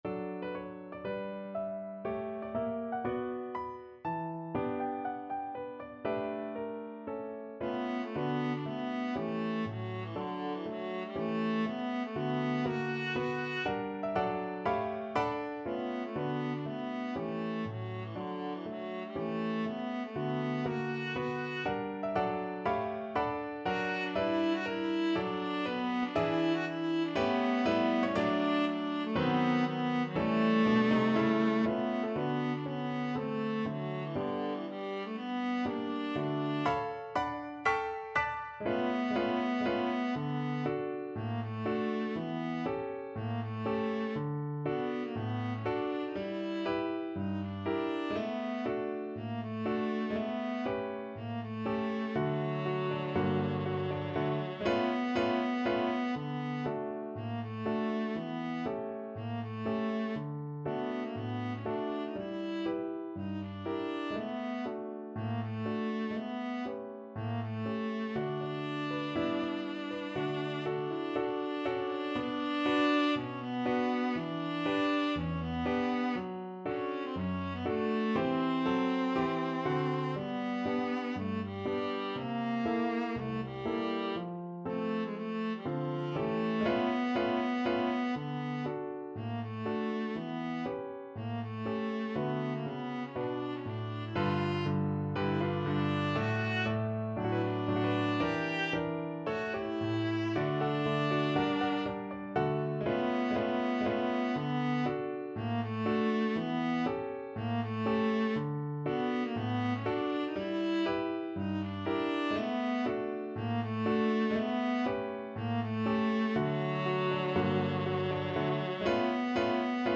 4/4 (View more 4/4 Music)
~ = 120 Moderato